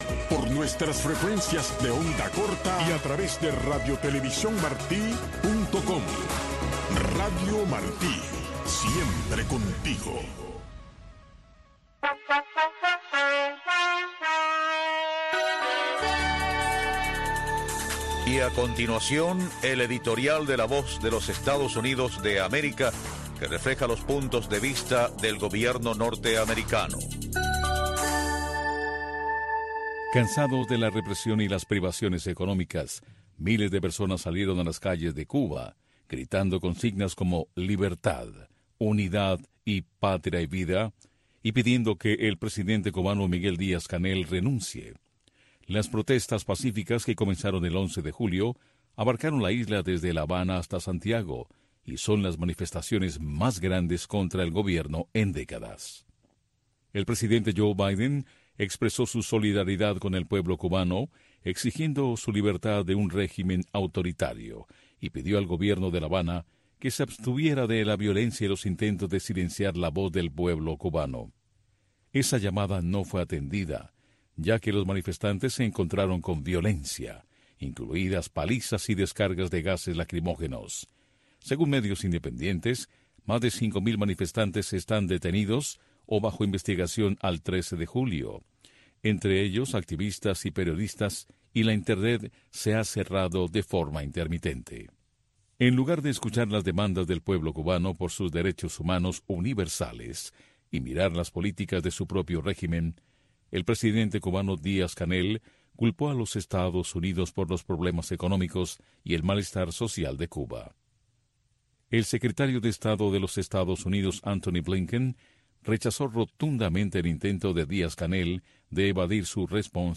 Radio Martí les ofrece una revista de entrevistas, información de la actualidad mundial vista desde el punto de vista, Entre Dos Rios.